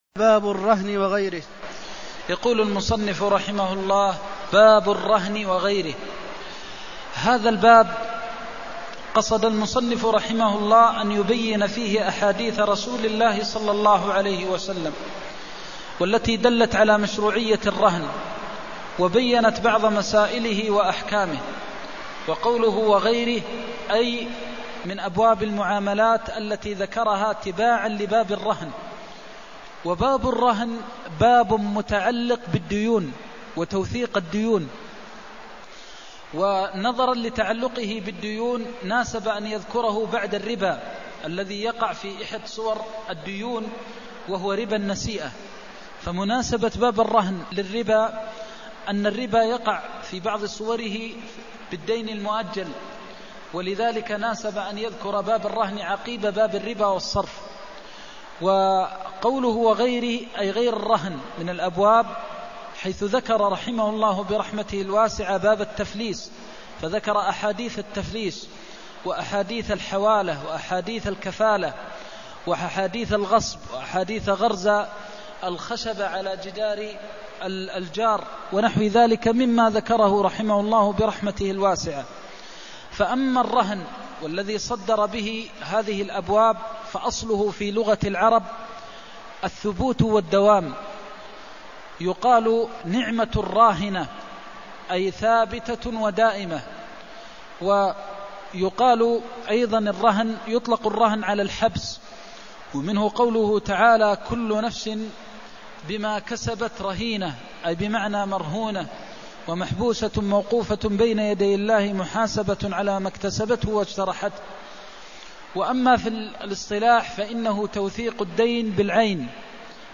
المكان: المسجد النبوي الشيخ: فضيلة الشيخ د. محمد بن محمد المختار فضيلة الشيخ د. محمد بن محمد المختار الرهن عند اليهود وغيرهم (265)